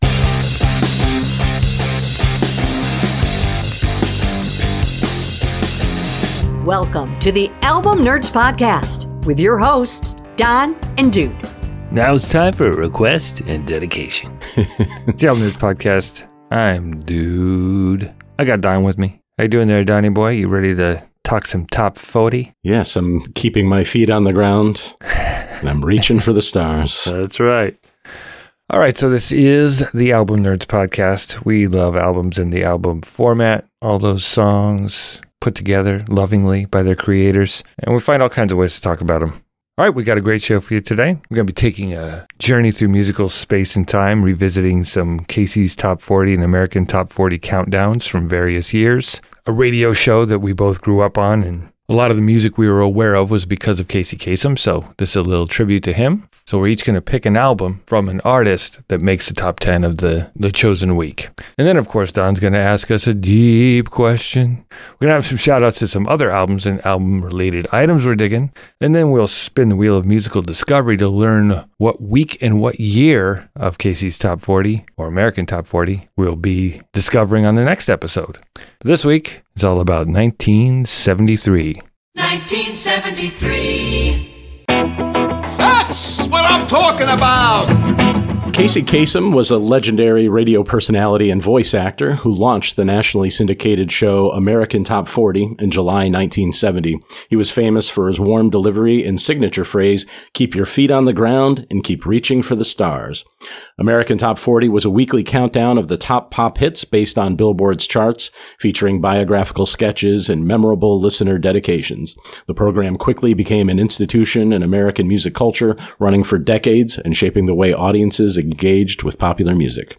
Album picks on a range of topics selected by the all knowing Wheel of Musical Destiny. Two friends and music nerds discuss classic albums across a variety of genres including rock, metal, country, hip-hop, r&b and pop.